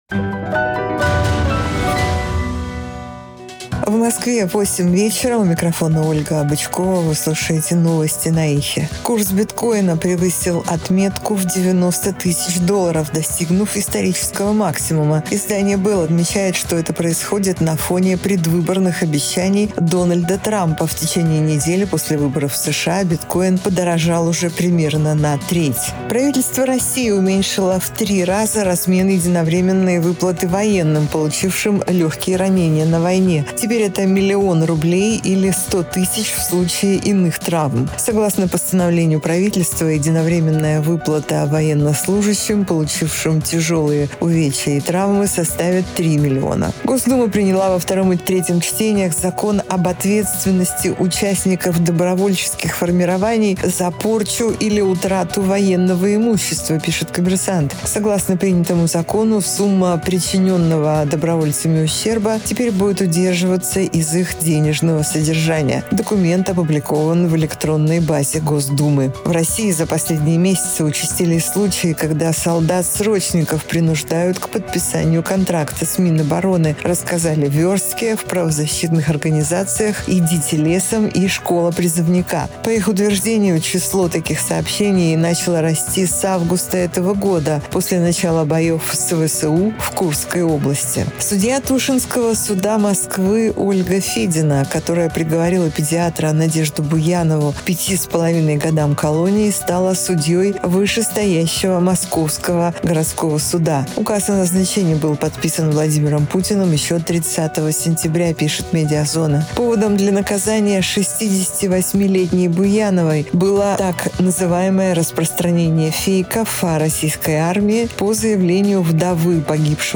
Слушайте свежий выпуск новостей «Эха»
новости 20:00